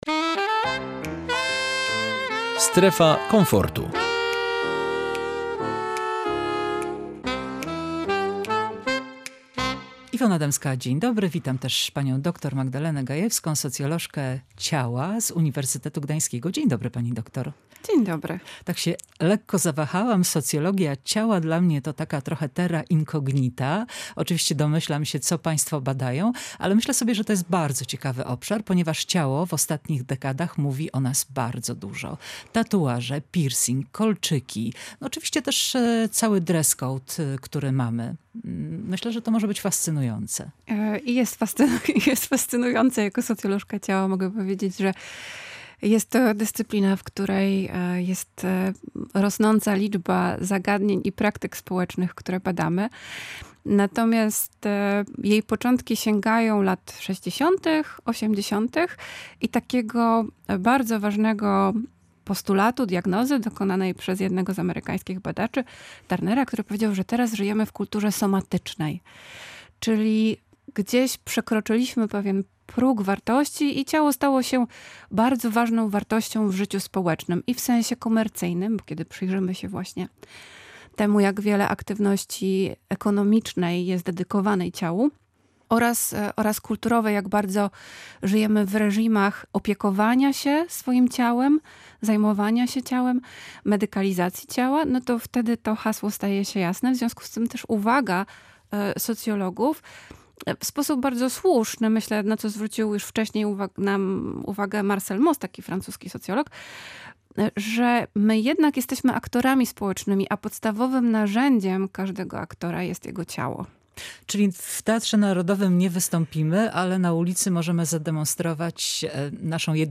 W audycji Strefa Komfortu rozmowa o tatuażach z psycholożką ciała